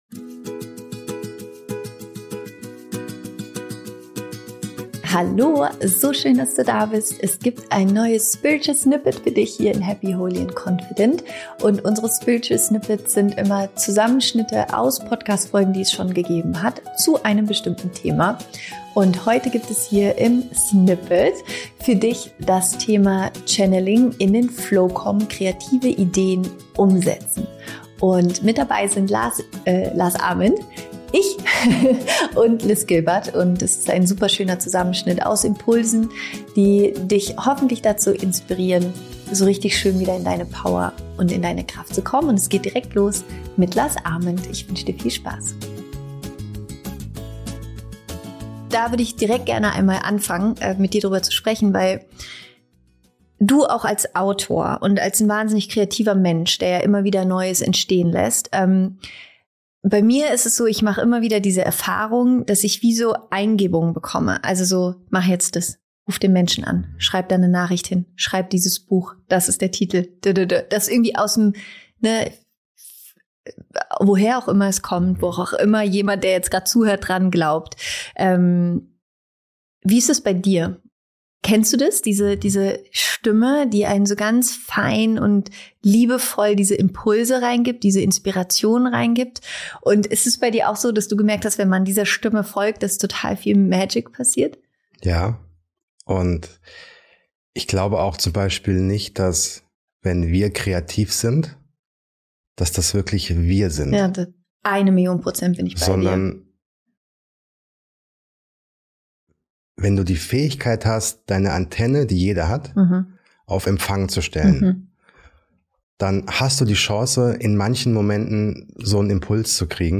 Die Spiritual Snippets sind ein Zusammenschnitt aus den inspirierendsten, empowerndsten & kraftvollsten Folgen meines Podcasts.